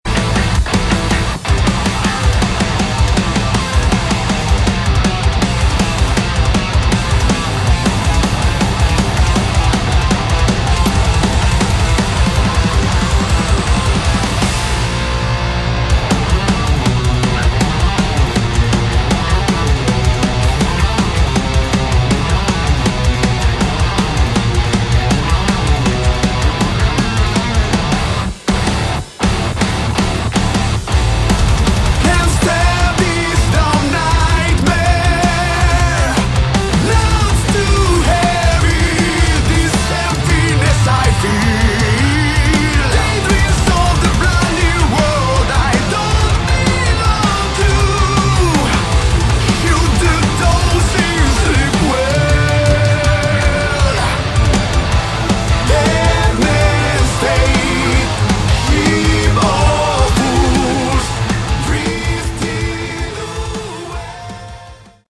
Category: Rock
drums
electric bass
electric guitar
vocals